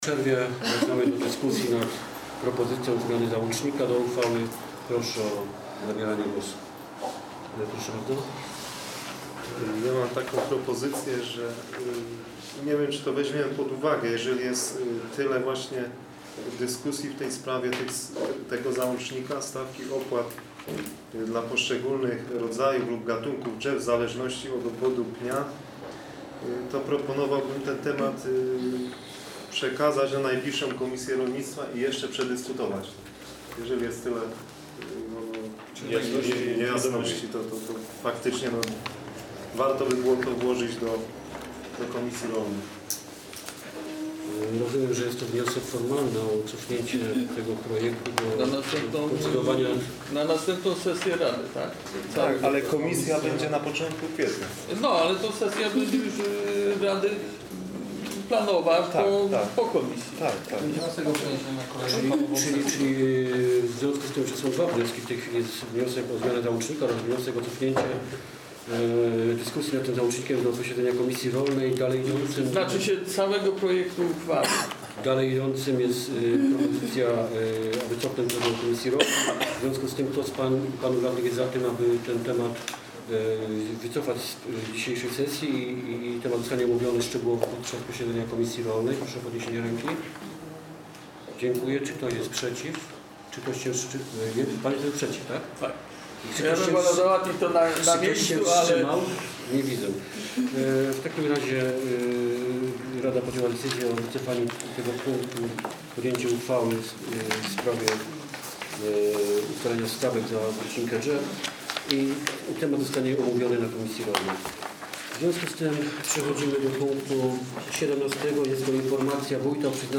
Nagranie: 42 sesja rady - 29 marca 2017 r. - część pierwsza